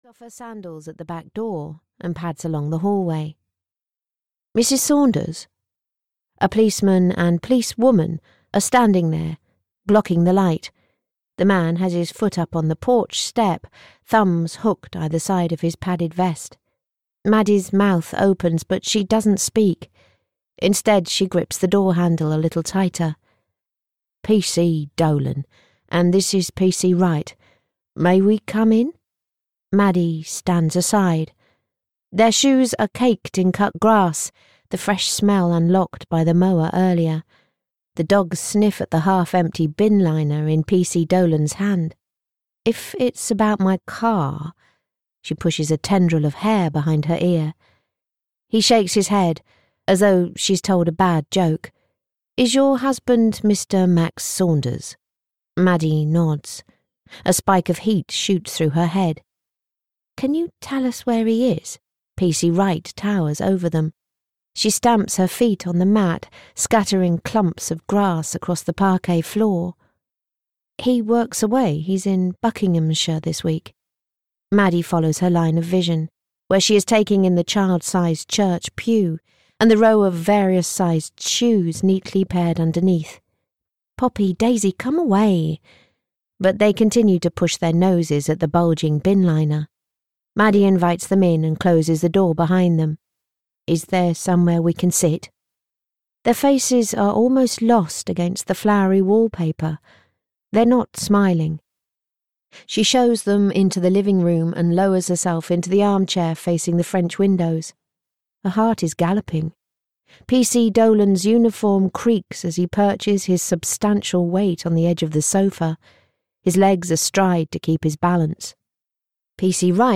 Every Little Secret (EN) audiokniha
Ukázka z knihy